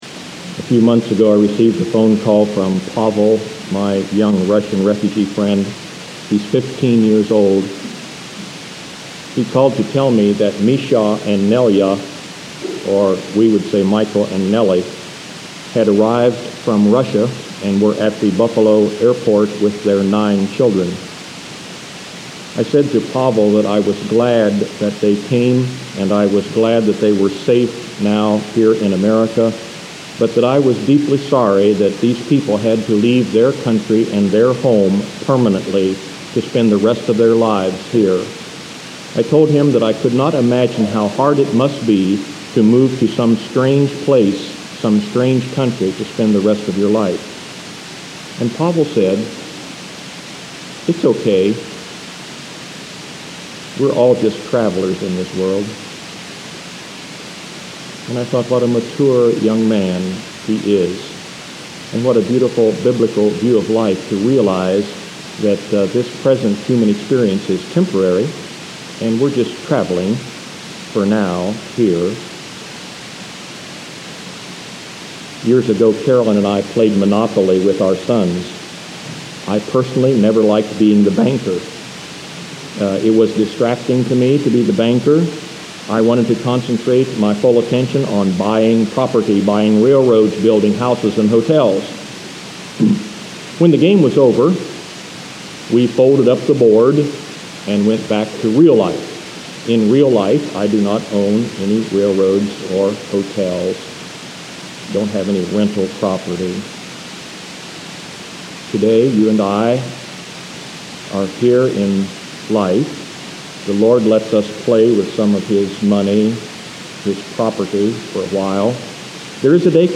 Note: the sound quality of this recording is pretty rough. I’ve enhanced as much as I could, but there is a lot of noise.